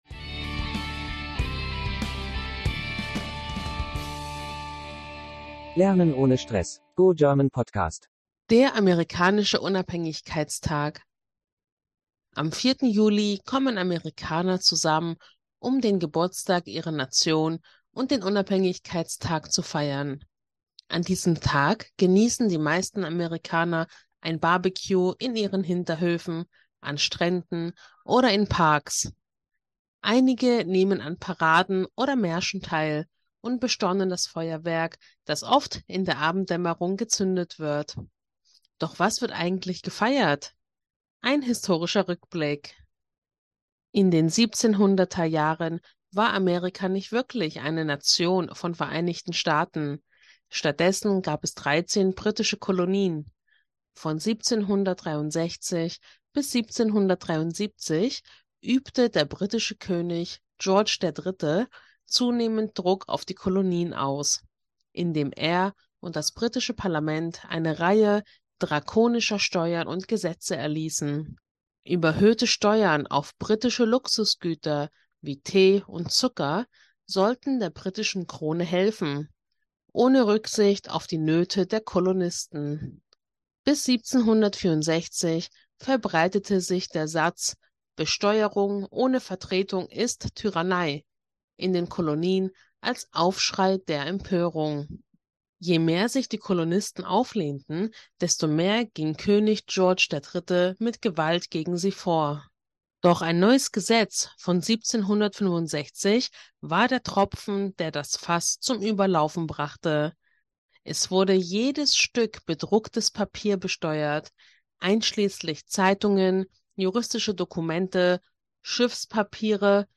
Eine kurze Geschichtsstunde in einfachem Deutsch!